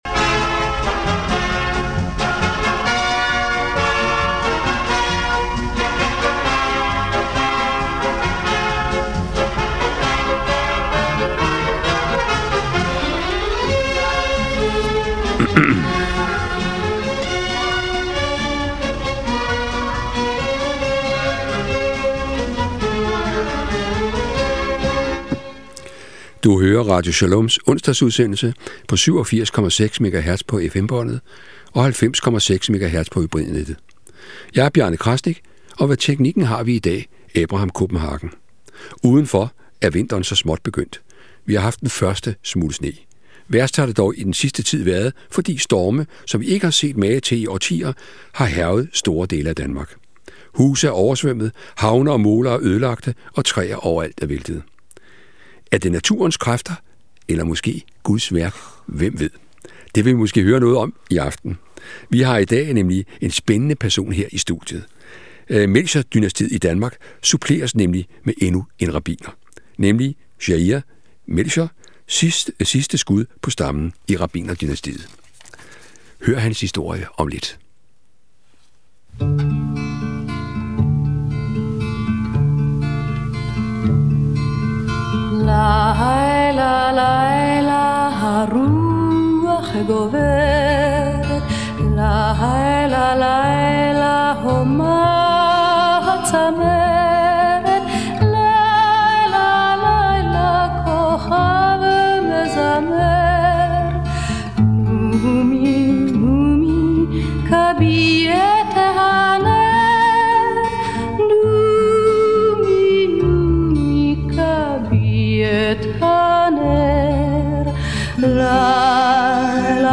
Interview
Interviews